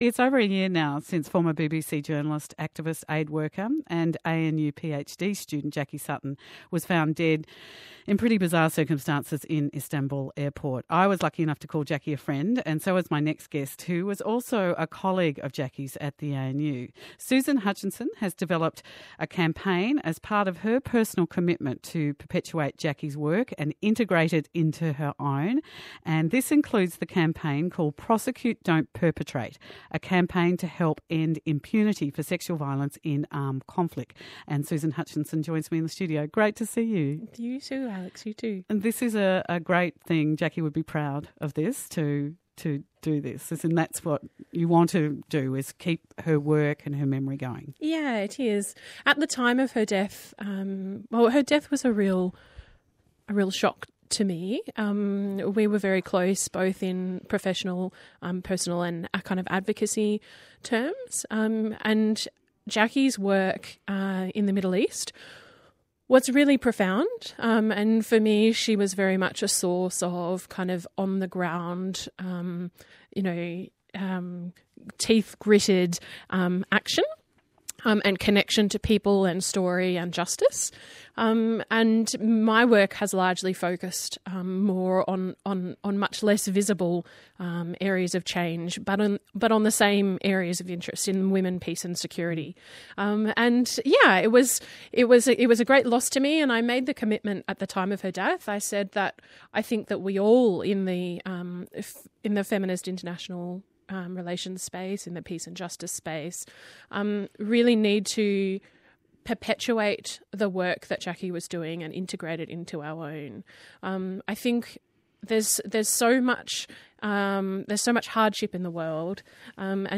You can listen to the podcast of the interview below.